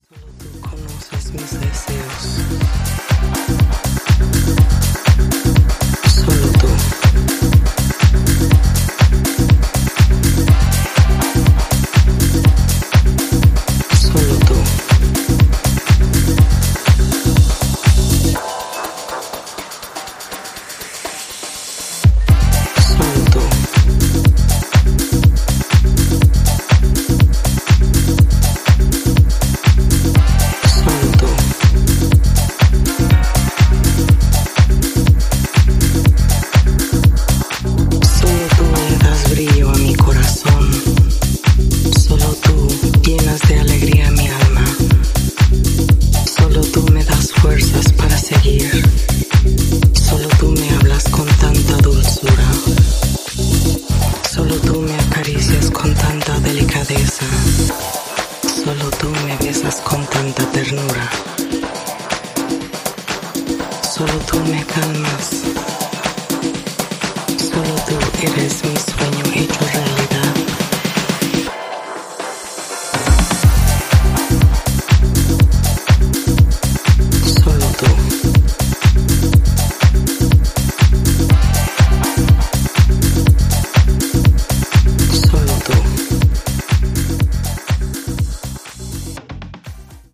ジャンル(スタイル) DEEP HOUSE / HOUSE